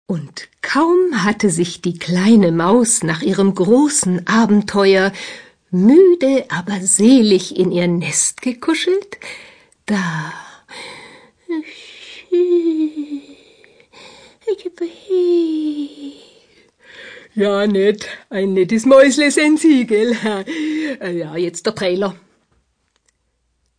Synchron und Offsprechen, Doku, Imagefilme, Werbung. Charakterrollen.
Sprechprobe: Sonstiges (Muttersprache):